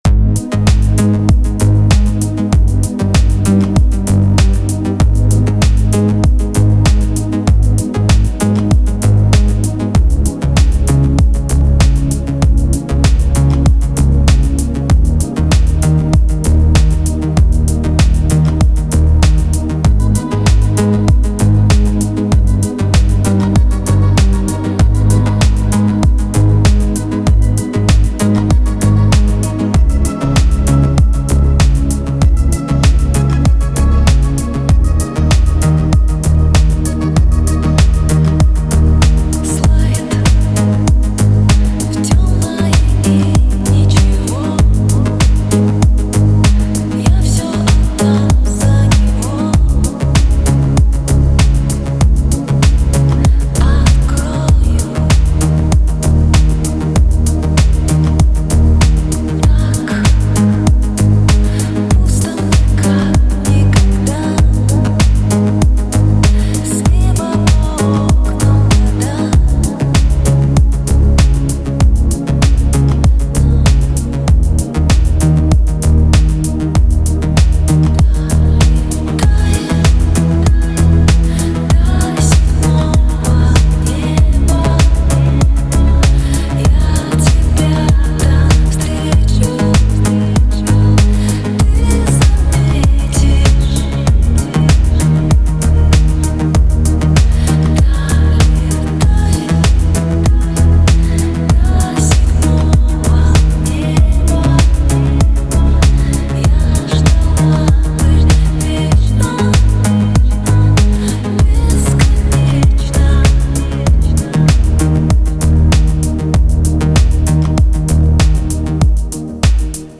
Категория: Deep House музыка